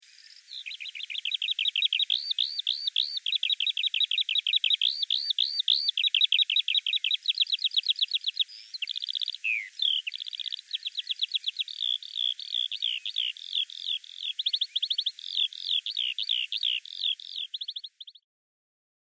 Gesang der Feldlerche
Der Gesang der Feldlerche wird zwar meistens in der Luft vorgetragen… hier gibt sie uns ein Ständchen vom Boden aus. Die Bestände dieser ursprünglich weit verbreiteten Art schwinden dramatisch.
feldlerche-gesang-natur-konkret.mp3